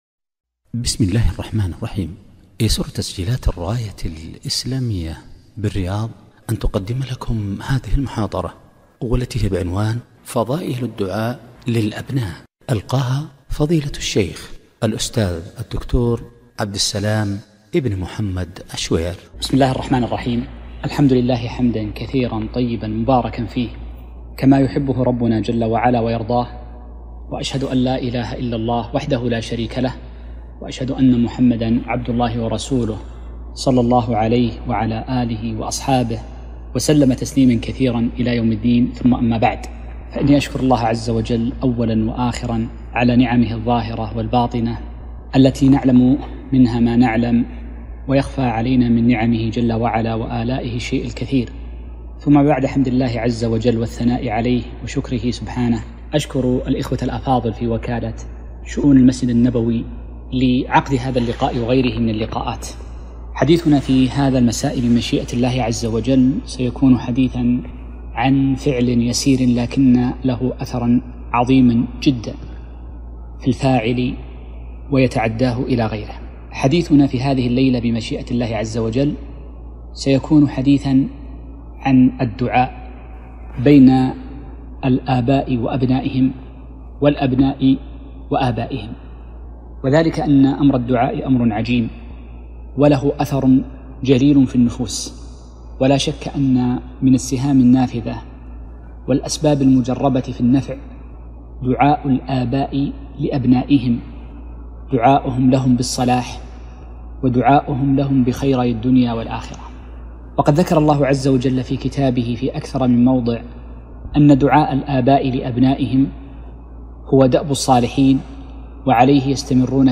محاضرة - فضائل الدعاء للأبناء